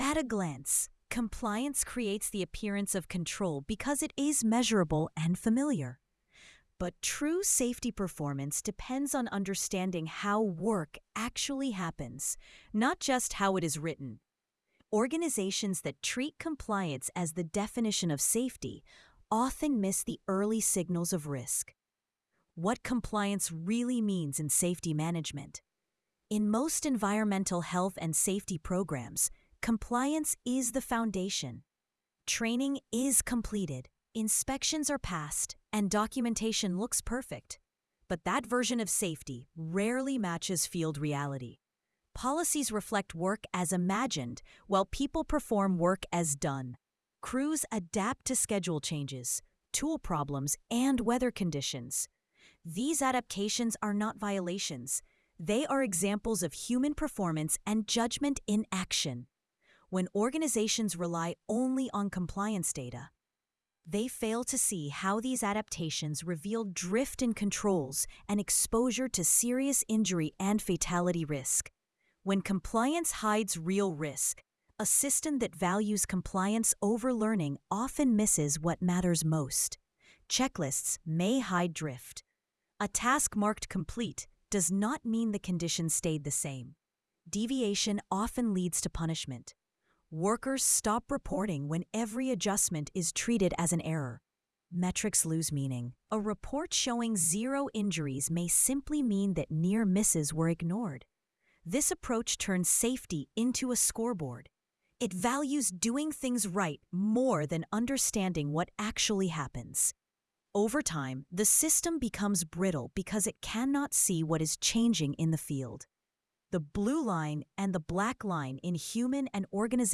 sage_gpt-4o-mini-tts_1x_2025-11-04T05_07_49-121Z.wav